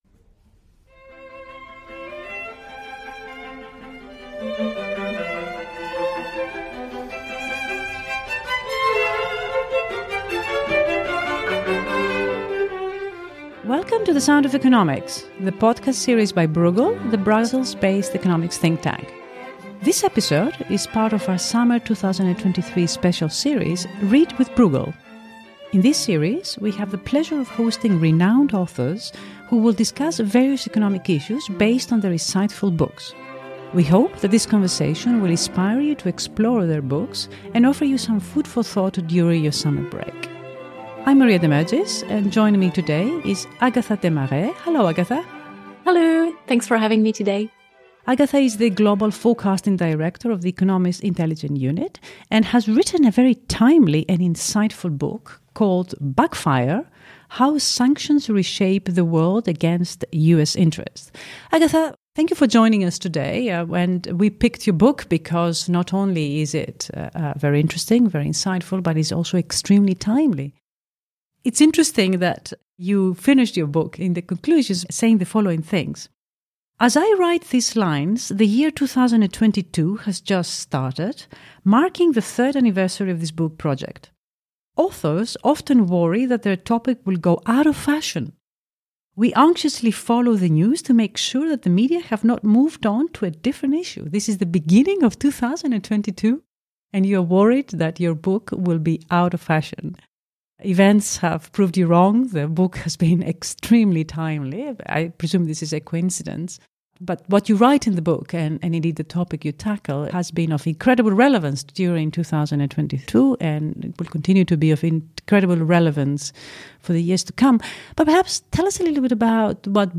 We hope this conversation will inspire you to explore their books and offer you some food for thought during your summer break.